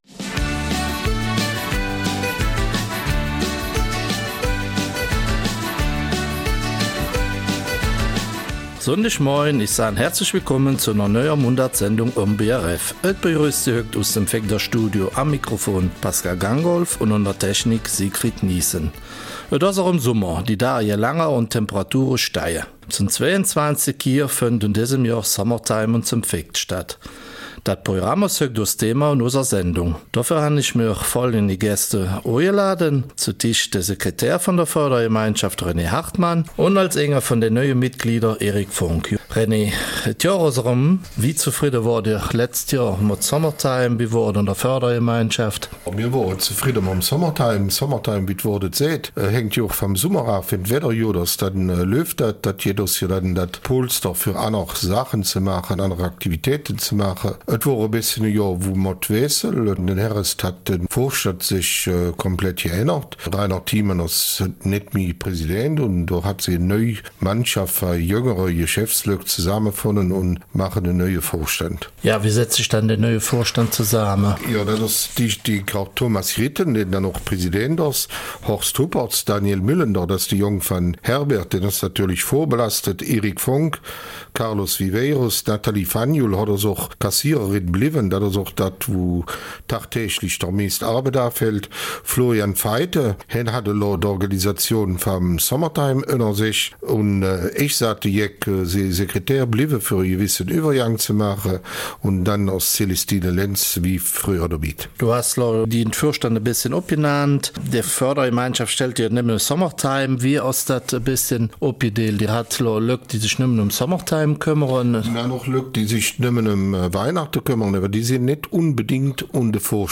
Eifeler Mundart: Summertime 2019 in St.Vith